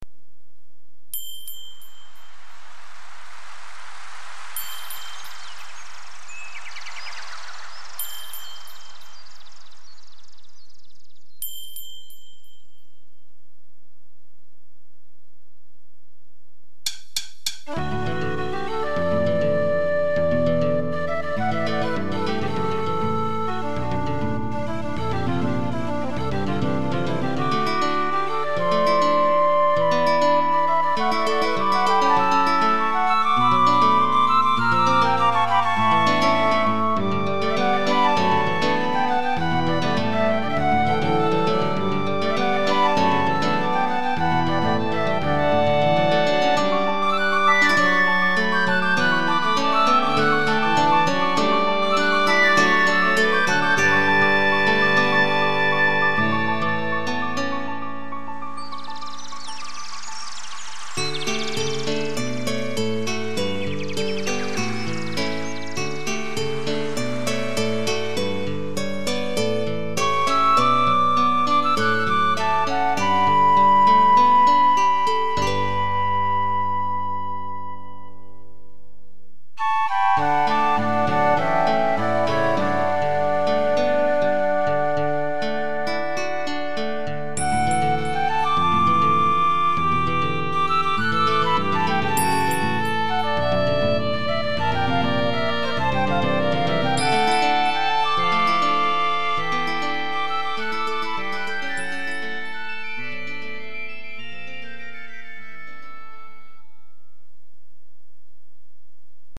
作品表示 Green Wind 解説 試作品…のようなもの。初めての明るい曲？
インストゥルメンタル